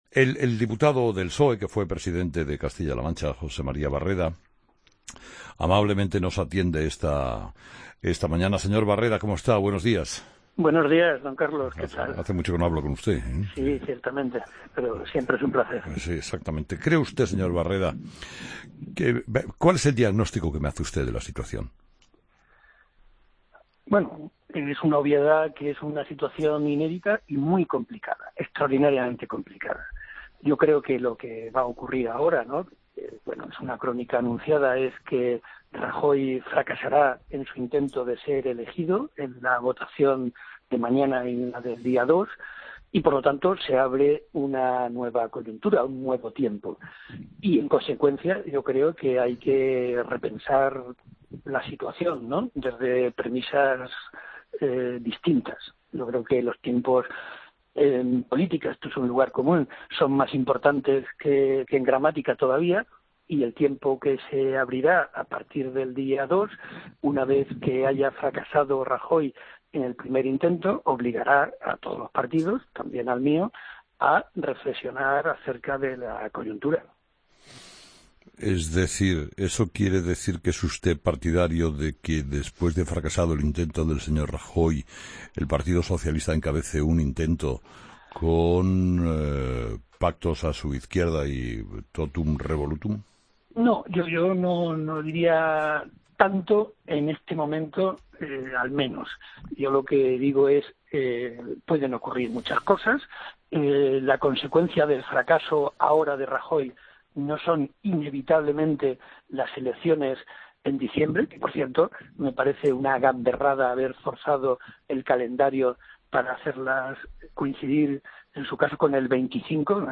AUDIO: Escucha la entrevista a José María Barreda, diputado del PSOE, en 'Herrera en COPE'